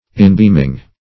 inbeaming - definition of inbeaming - synonyms, pronunciation, spelling from Free Dictionary Search Result for " inbeaming" : The Collaborative International Dictionary of English v.0.48: Inbeaming \In"beam`ing\, n. Shining in.
inbeaming.mp3